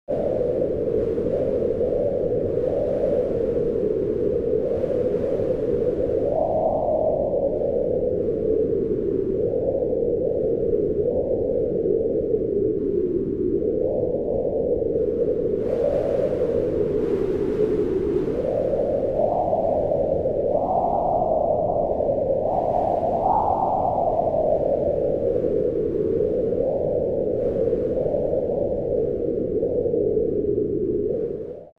دانلود آهنگ باد 31 از افکت صوتی طبیعت و محیط
دانلود صدای باد 31 از ساعد نیوز با لینک مستقیم و کیفیت بالا
جلوه های صوتی